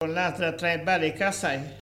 Locutions vernaculaires
Catégorie Locution